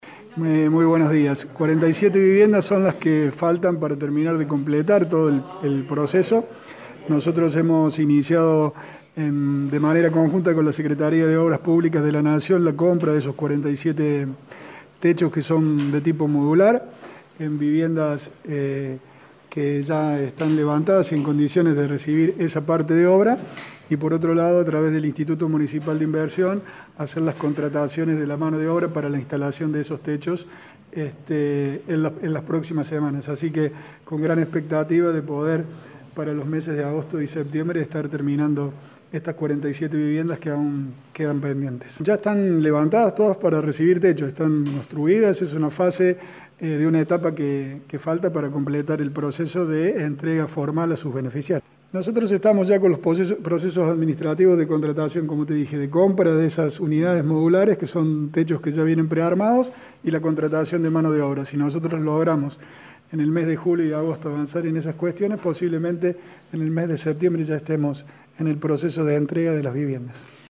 En el día de ayer, el intendente interino Pablo Rosso explicó a Radio Show que las casas se entregarían en septiembre.